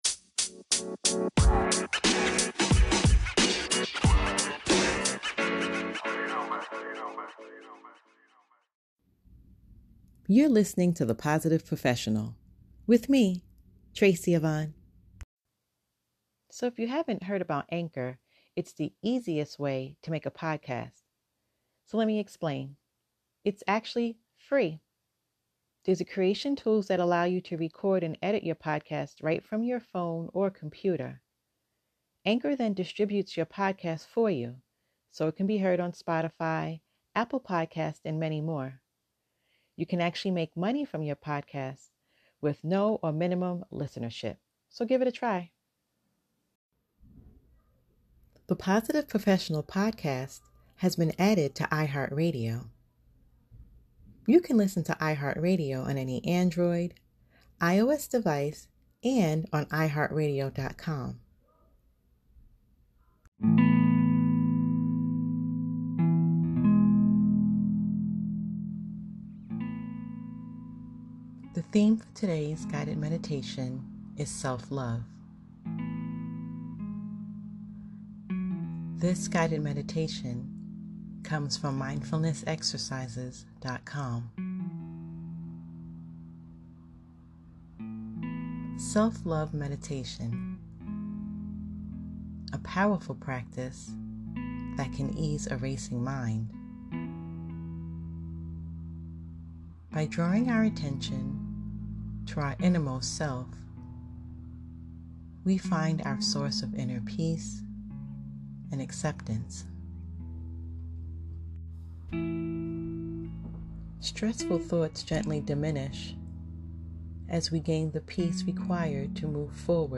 Self-Love Guided Meditation